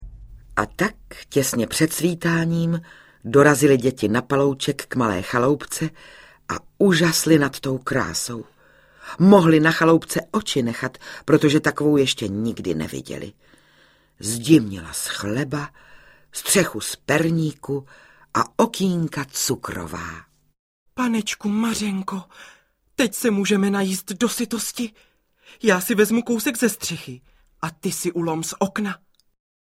Pohádky II audiokniha
pohadky-ii-audiokniha